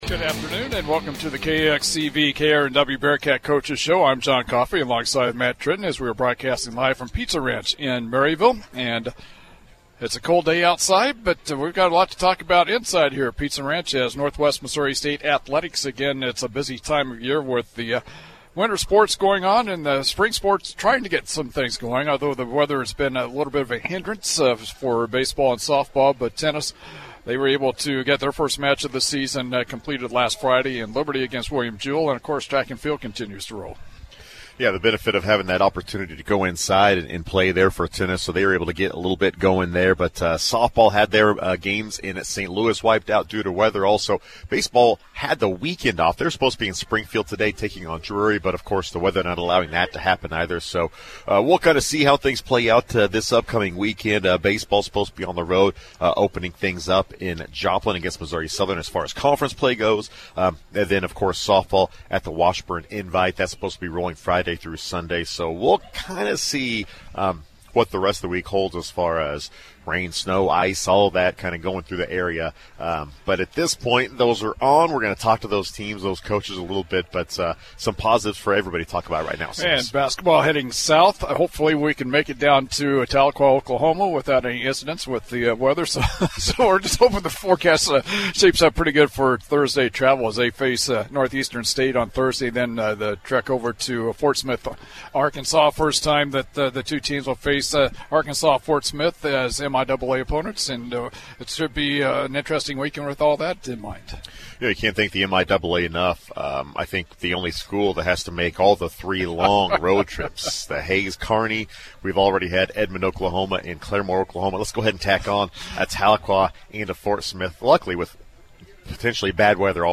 February 18 Bearcat Coaches Show
Local Sports